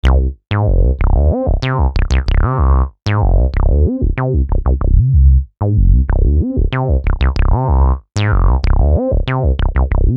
Bass 23.wav